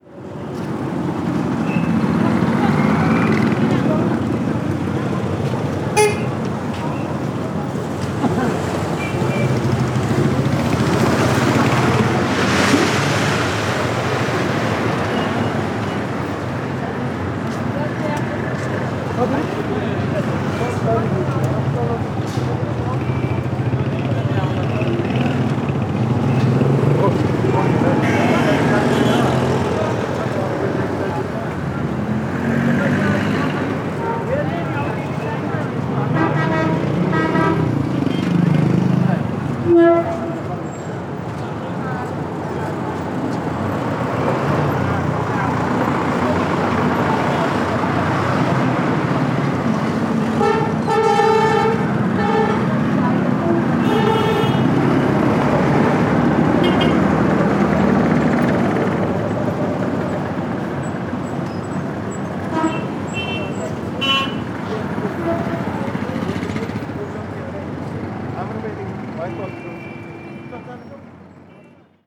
Ambiente de tráfico en Katmandú, Nepal
acelerón
bocina
moto
motor
Sonidos: Transportes
Sonidos: Ciudad